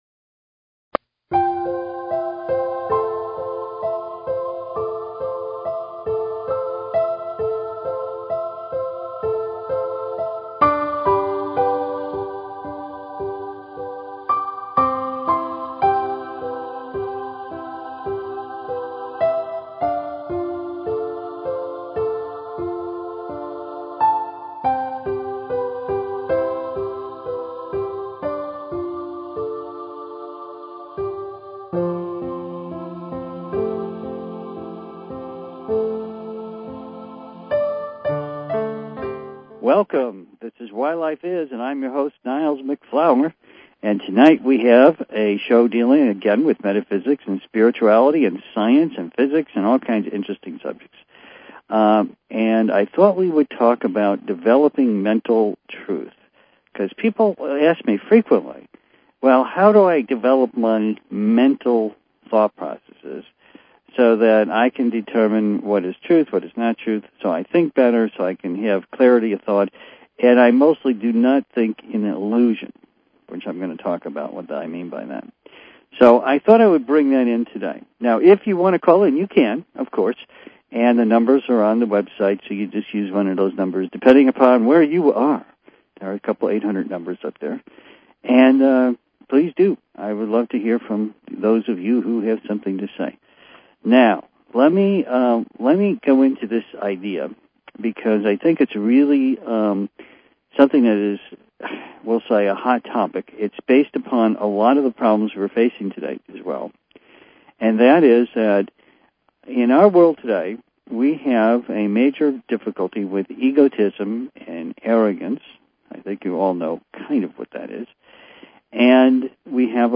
Subscribe Talk Show Why Life Is...